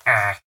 assets / minecraft / sounds / mob / villager / no2.ogg